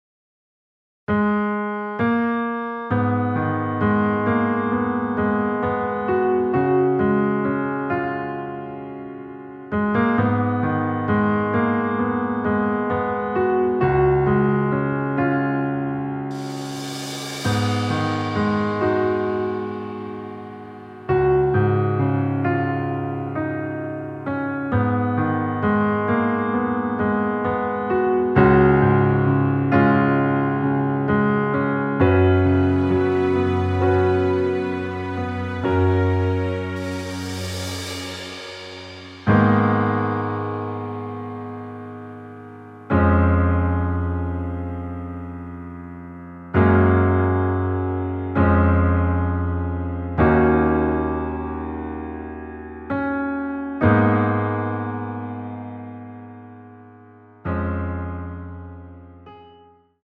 F#
앞부분30초, 뒷부분30초씩 편집해서 올려 드리고 있습니다.